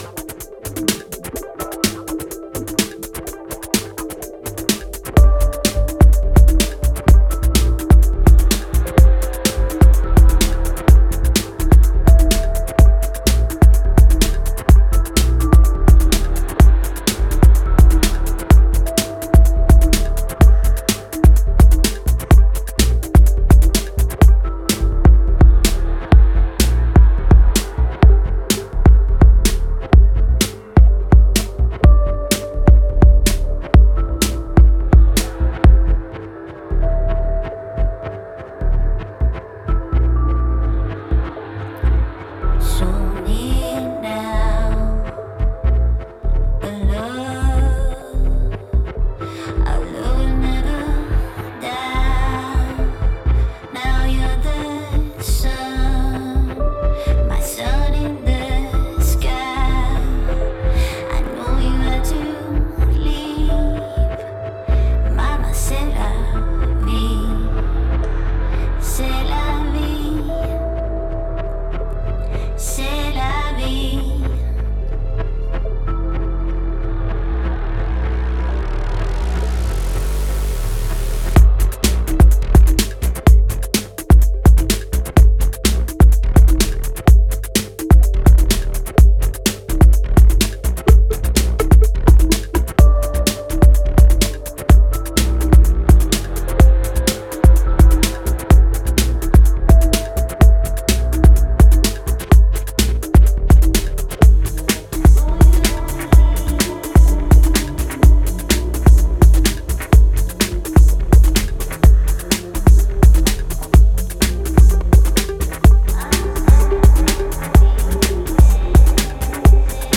dancefloor bomb